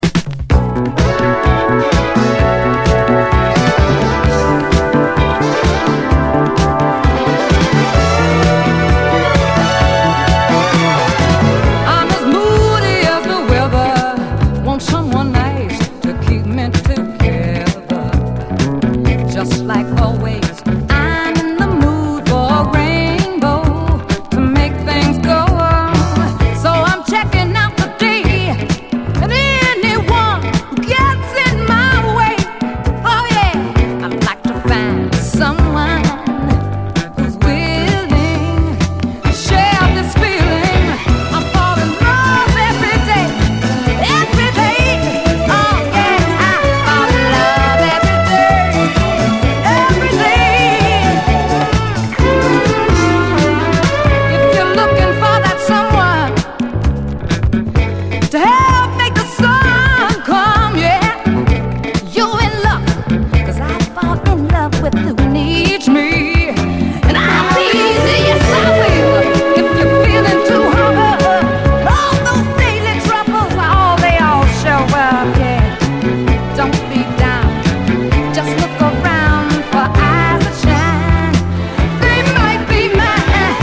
埋もれたグルーヴィー・ブルーアイド・ソウル！
えぐるようにスリリング＆ファンキーなイントロがまずヤバく、白人バンドならではの甘いハーモニーはプリAOR的！